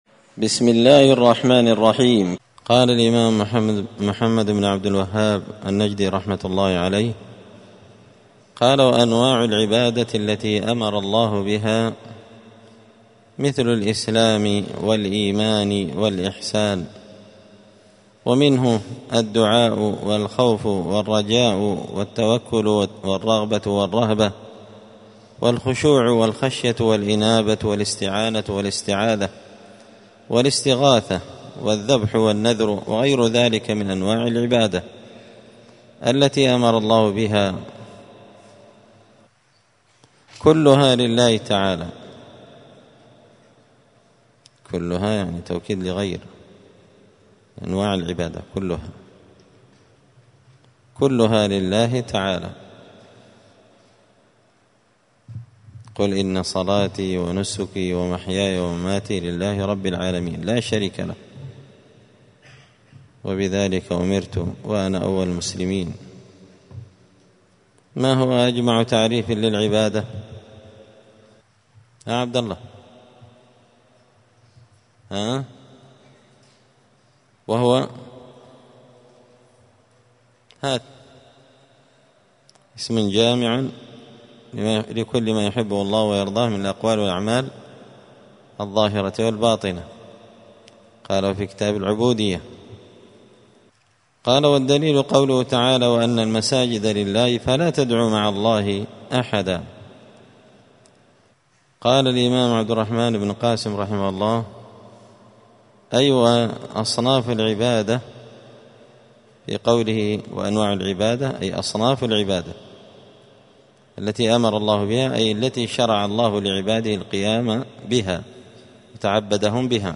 *الدرس الثالث عشر (13) من قوله {وأنواع العبادات التي أمر الله بها مثل الإسلام والإيمان والإحسان ومنه الدعاء…}*
مسجد الفرقان قشن_المهرة_اليمن